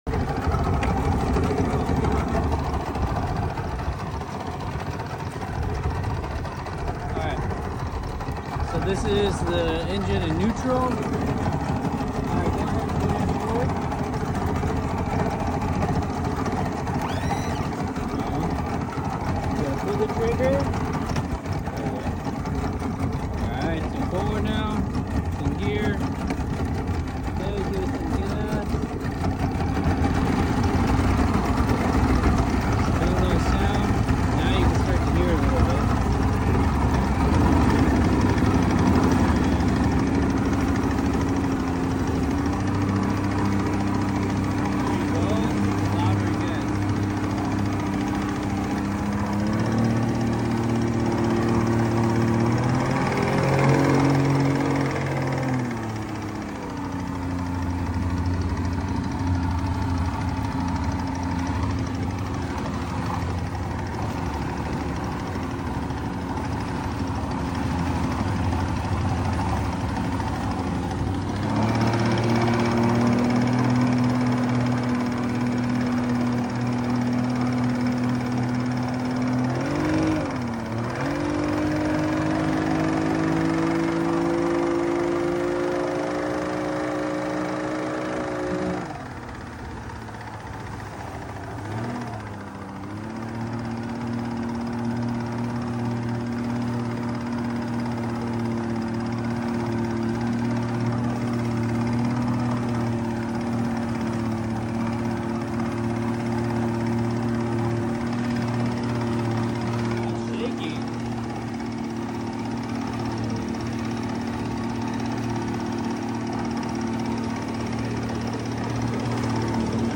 1989 70hp Yamaha 2 stroke sound effects free download
1989 70hp Yamaha 2 stroke making a clicking sound, it sounds like it’s coming from the exhaust area.